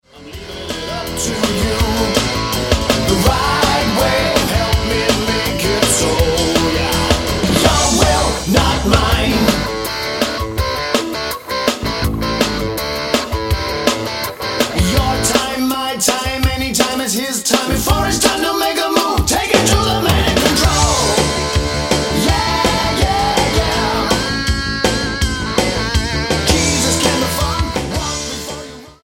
STYLE: Pop
a gutsy blues rock voice
retro old time boogie rock